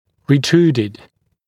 [rɪ’truːdɪd][ри’тру:дид]расположенный кзади